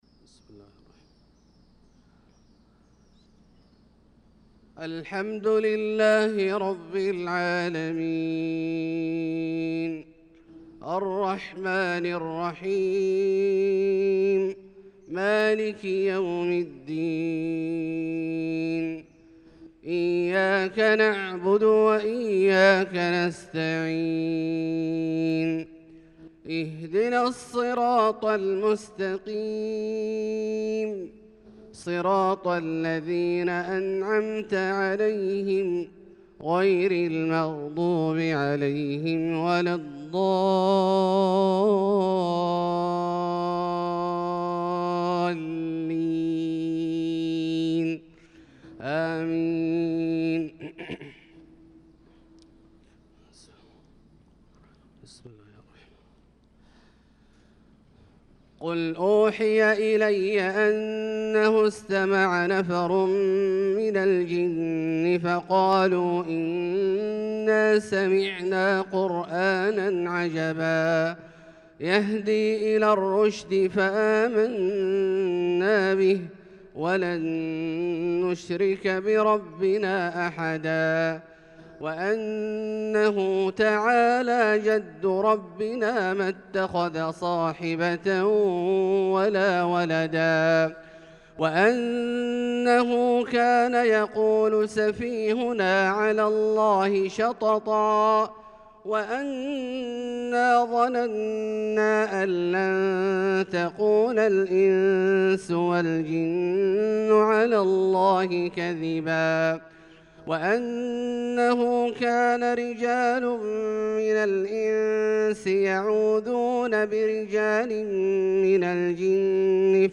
صلاة الفجر للقارئ عبدالله الجهني 12 شوال 1445 هـ
تِلَاوَات الْحَرَمَيْن .